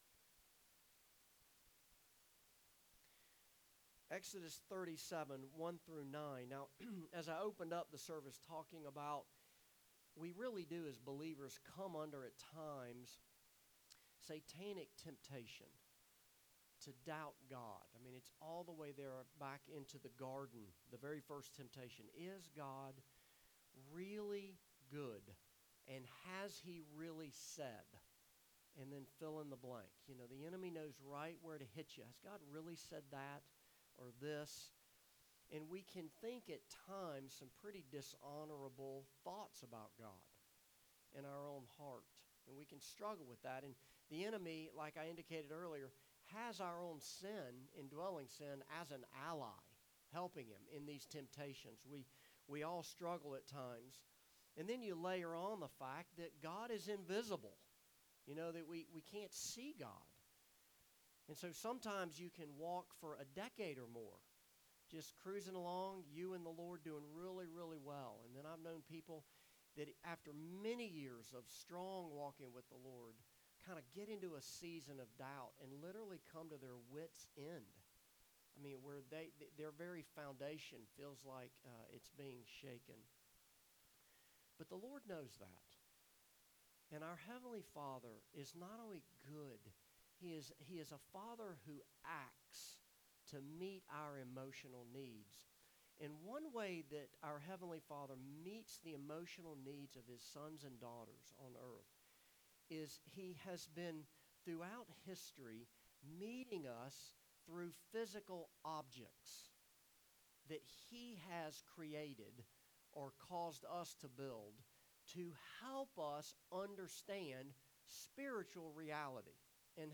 Passage: Exodus 37:1-9 Service Type: Morning Service